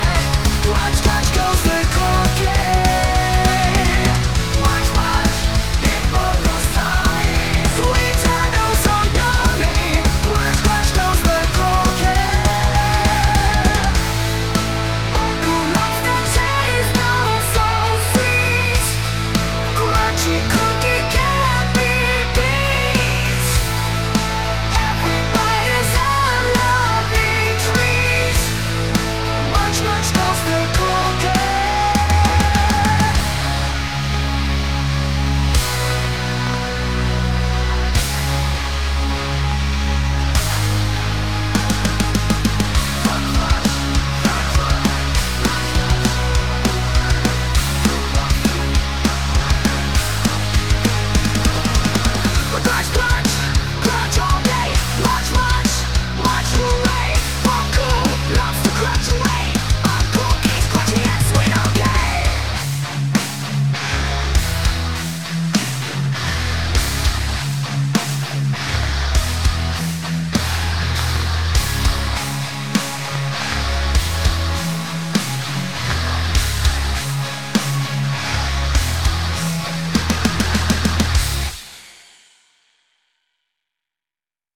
crunch.mp3